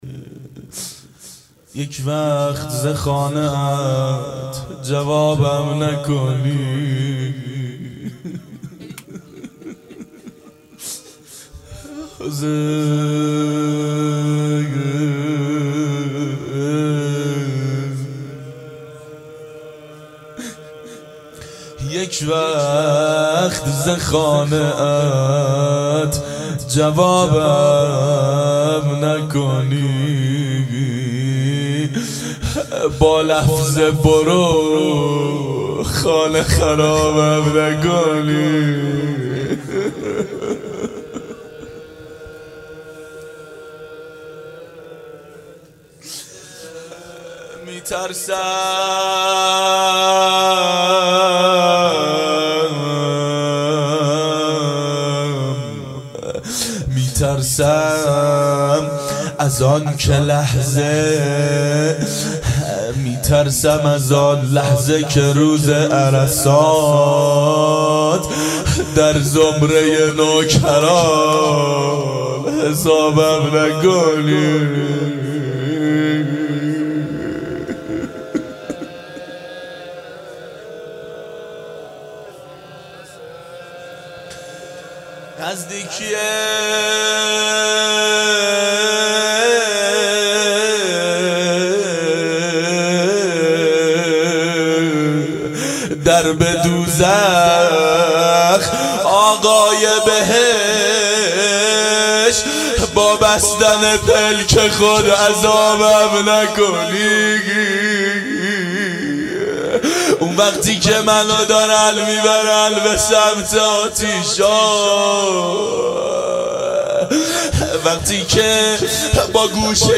• دهه اول صفر سال 1390 هیئت شیفتگان حضرت رقیه س شب سوم (شام غریبان)